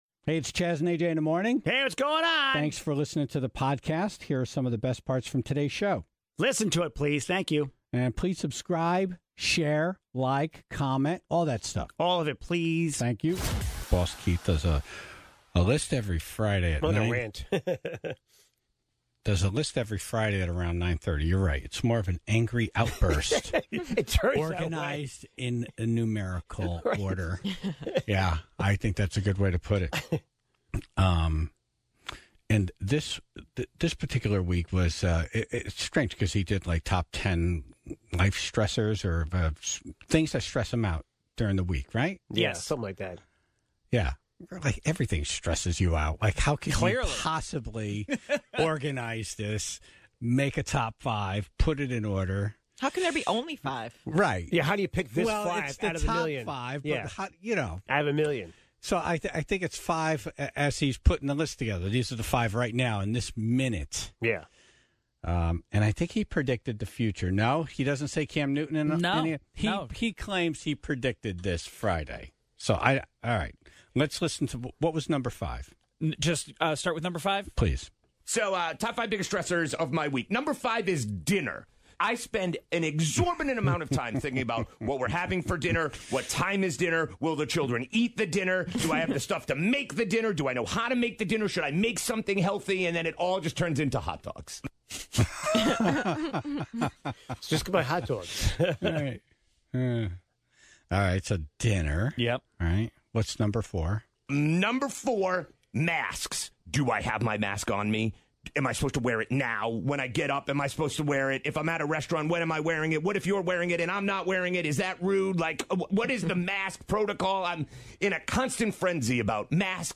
(5:55) A Florida man was arrested after attempting four separate car jackings, all of which failed. (10:31) Senator Blumenthal was on to talk about the President's case of coronavirus, and how he found out.
Everyone taste-tested them on the air, together.